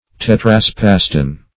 Search Result for " tetraspaston" : The Collaborative International Dictionary of English v.0.48: Tetraspaston \Tet`ra*spas"ton\, n. [NL., fr. Gr. te`tra- (see Tetra- ) + ? to draw, pull.]